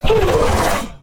combat / creatures / ryuchi / she / hurt1.ogg
hurt1.ogg